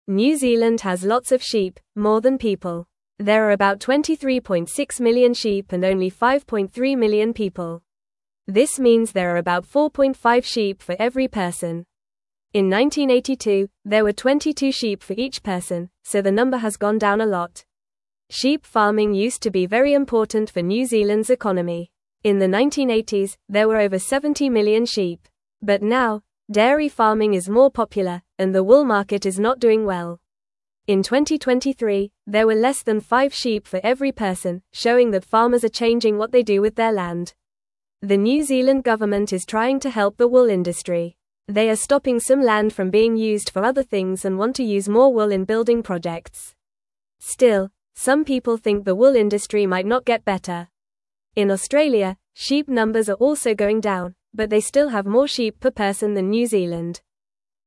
Fast
English-Newsroom-Lower-Intermediate-FAST-Reading-New-Zealands-Sheep-Numbers-Are-Getting-Smaller.mp3